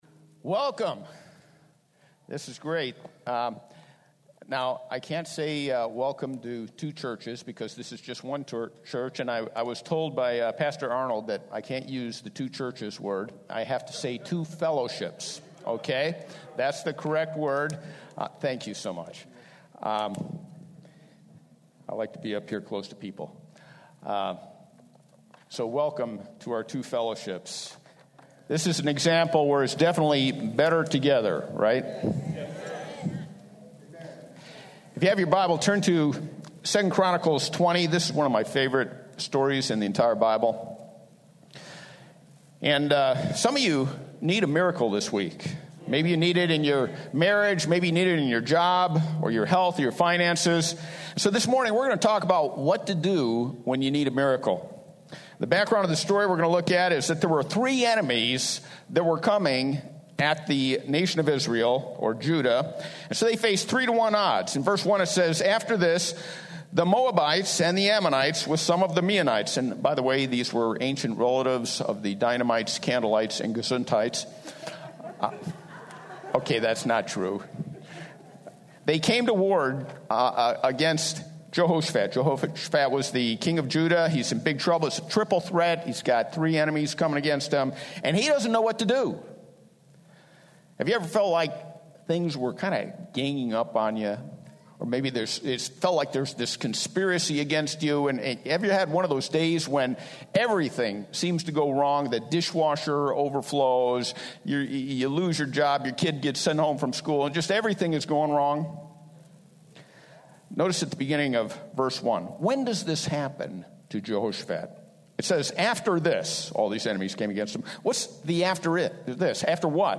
Category: Teachings